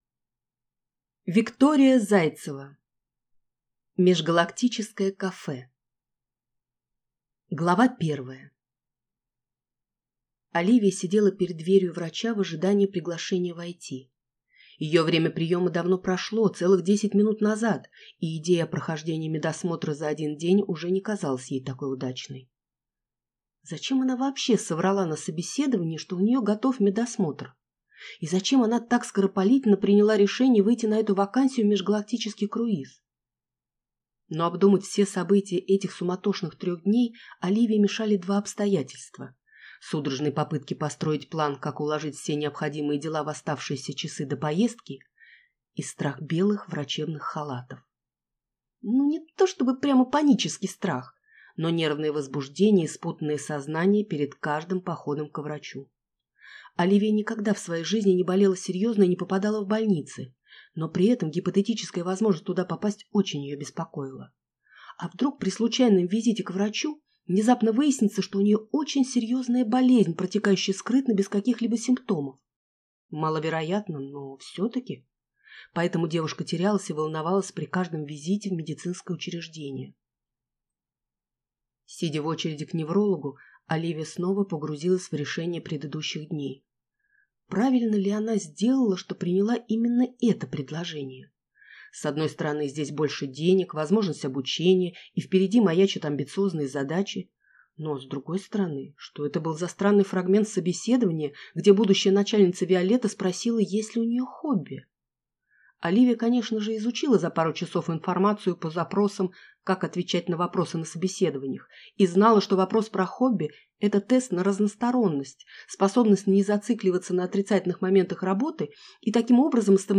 Аудиокнига Межгалактическое кафе | Библиотека аудиокниг